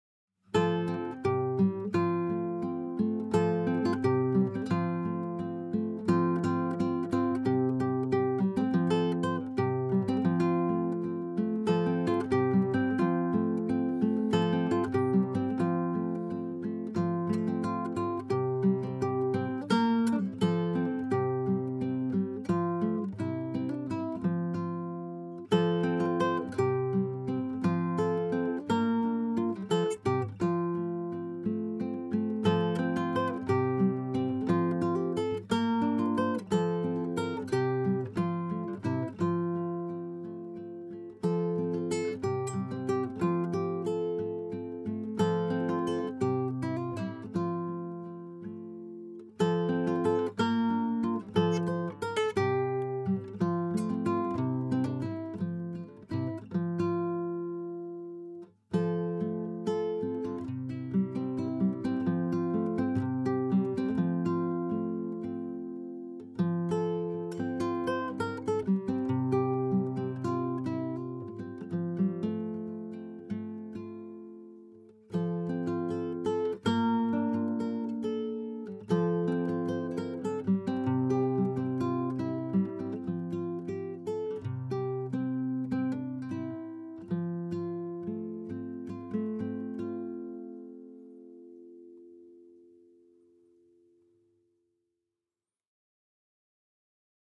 Some original guitar samplers: